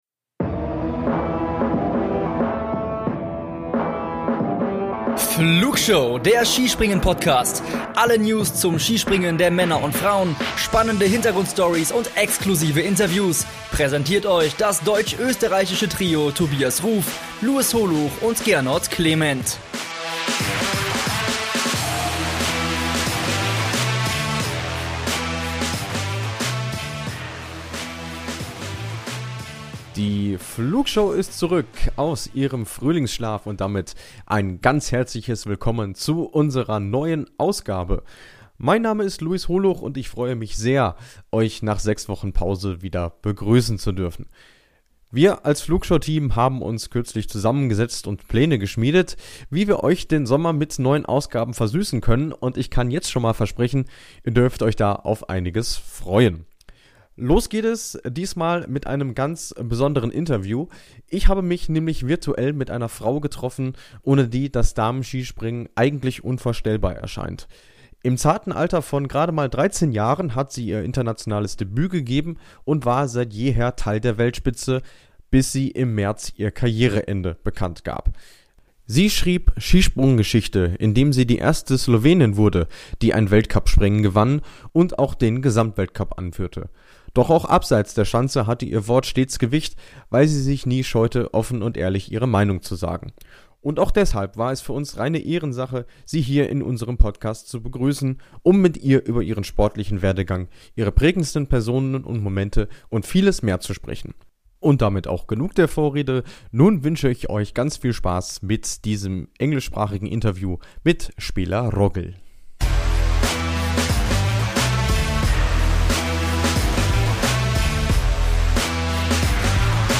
Špela Rogelj im Interview ~ Wintersport Podcast